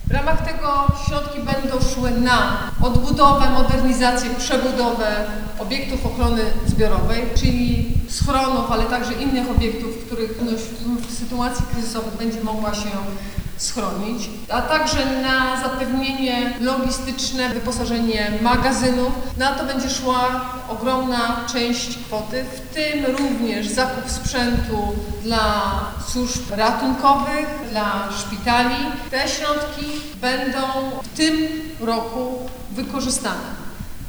Na co dokładnie zostaną przeznaczone te środki? Wyjaśnia Anna Żabska, wojewoda dolnośląska.